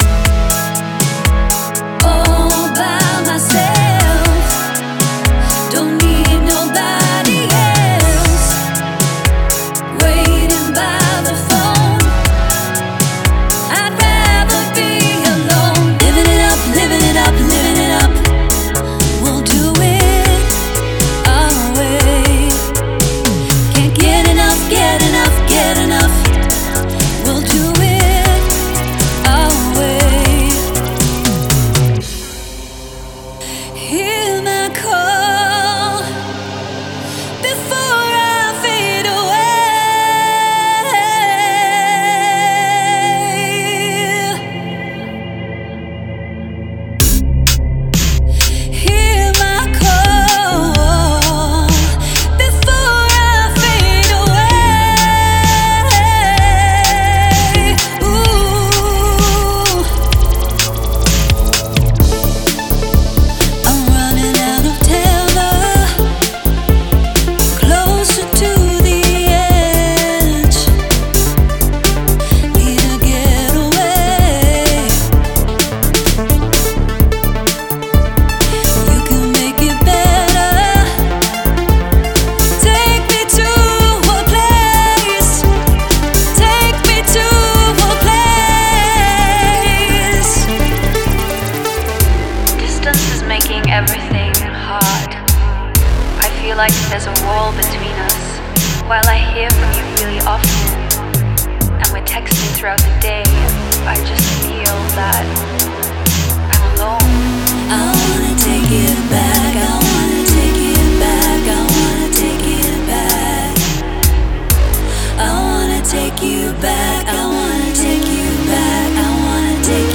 Genre:Pop
85 Full Vocal Hooks
35 Individual Vocal Harmonies